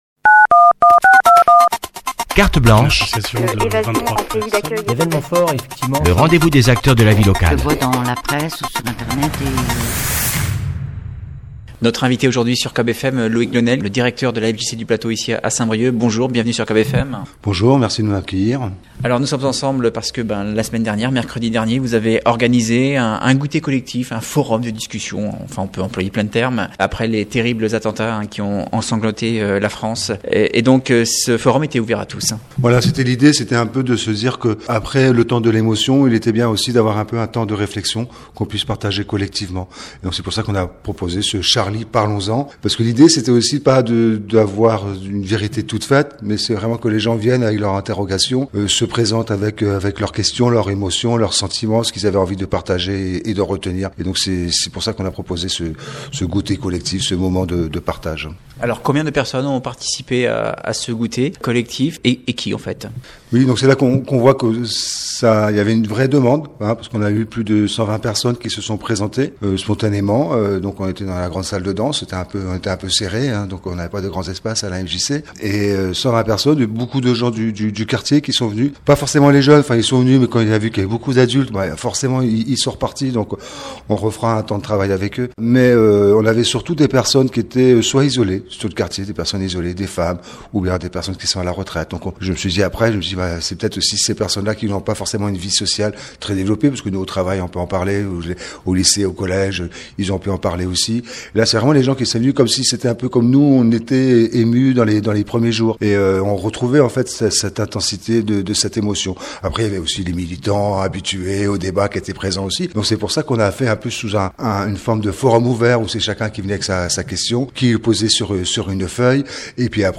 mjcplateaudebat.mp3